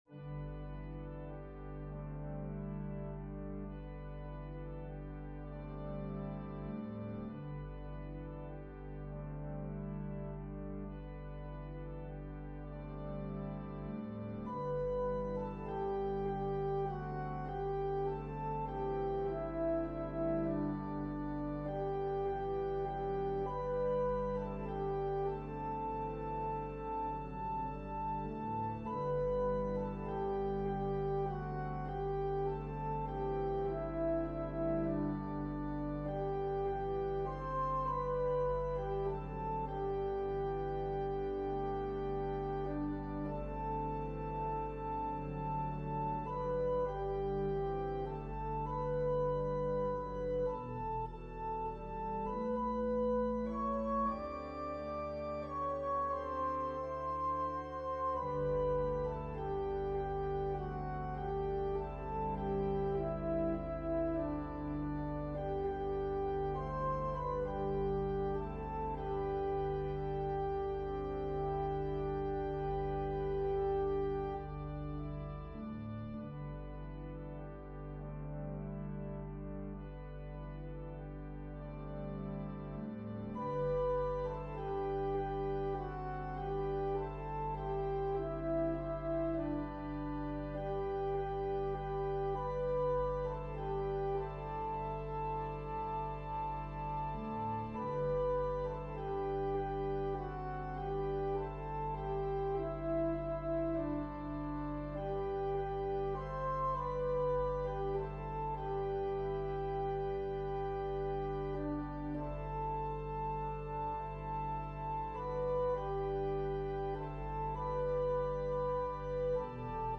Voicing/Instrumentation: Organ/Organ Accompaniment We also have other 5 arrangements of " Softly and Tenderly, Jesus is Calling ".